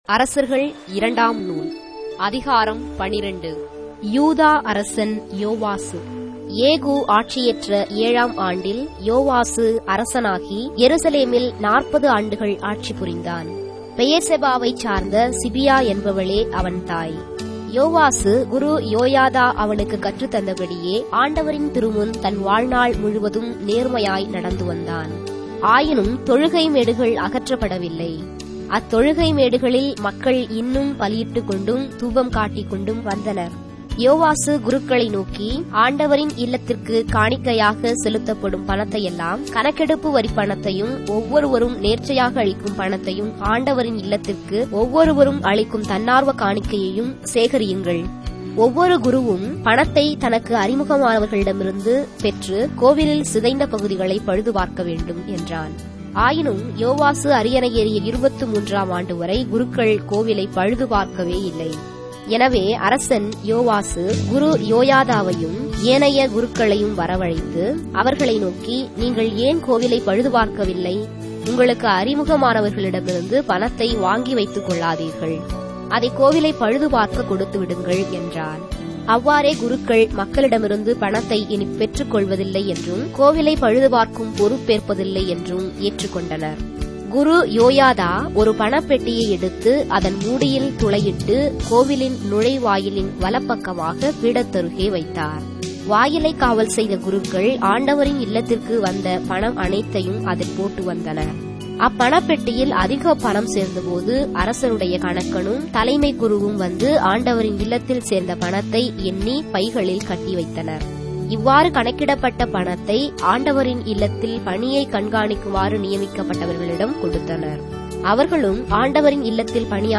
Tamil Audio Bible - 2-Kings 20 in Ecta bible version